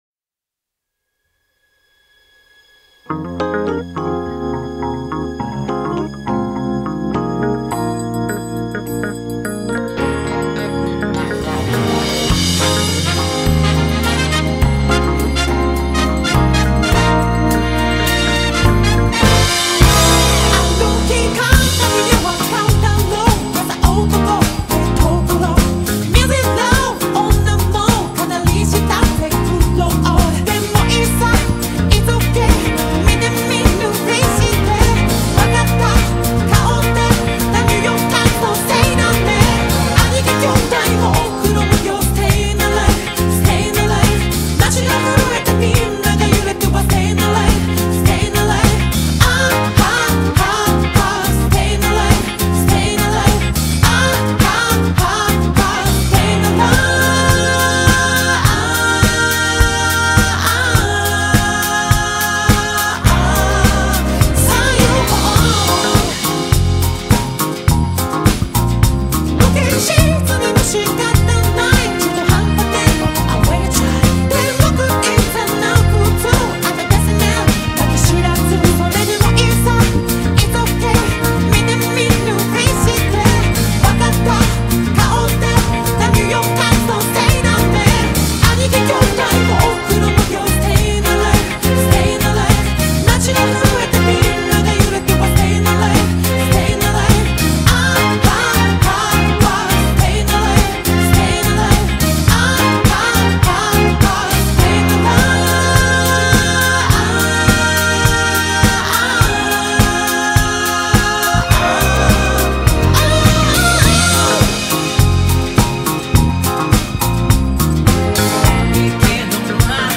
Soundtrack, Pop